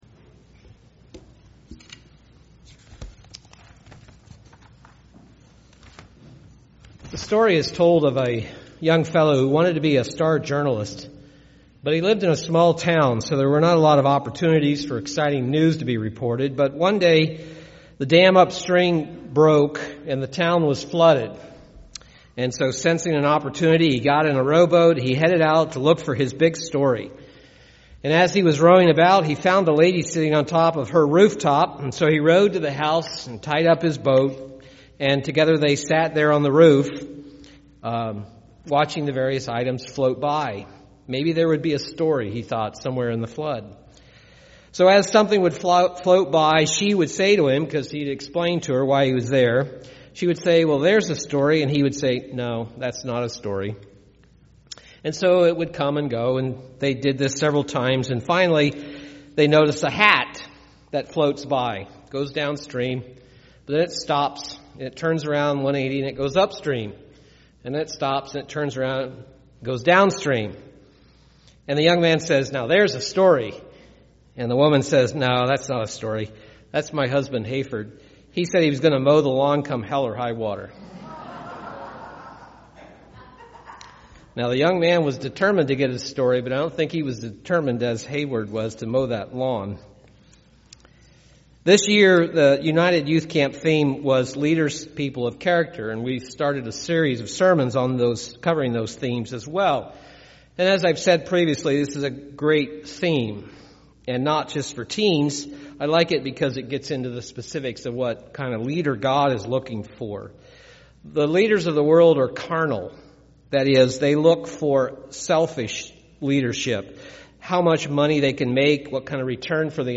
Given in Milwaukee, WI
Print The 3rd component of Godly character is Determination UCG Sermon Studying the bible?